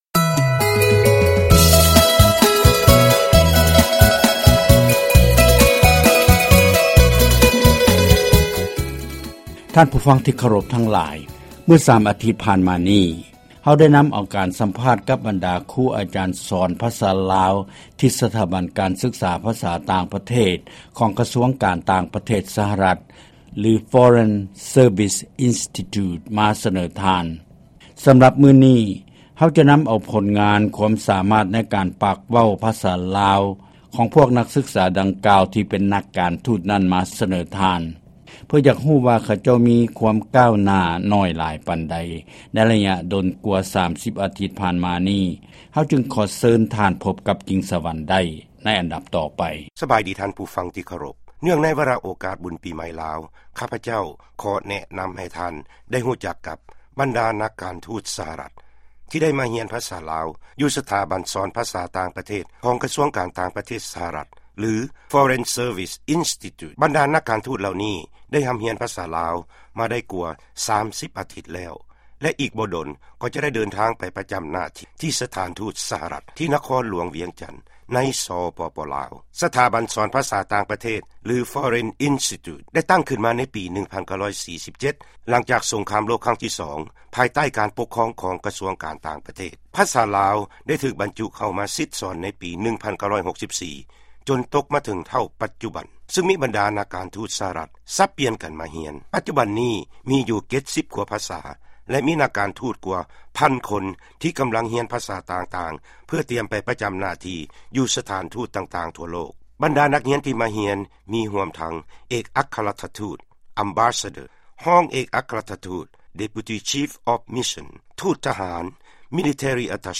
ຟັງການສຳພາດ ບັນດານັກການທູດ ສະຫະລັດ ປາກພາສາລາວ